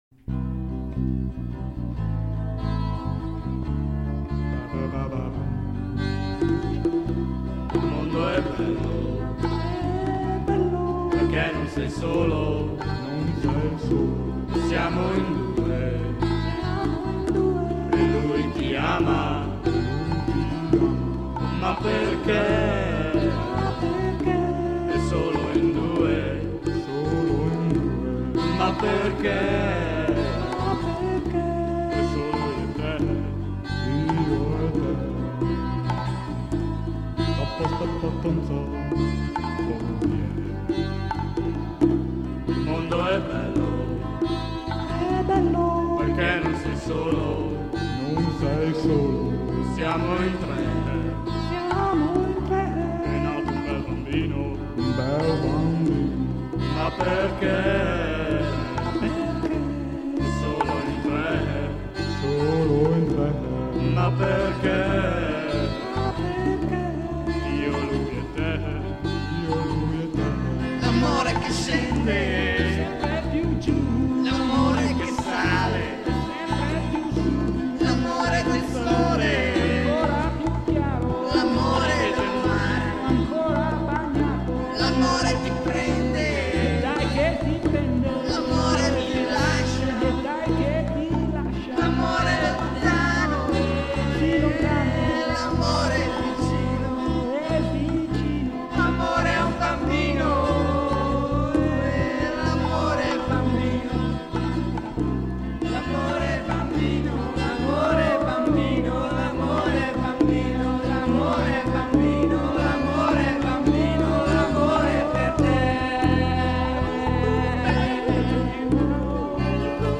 vocal & backing vocals
basso postumo & bonghetti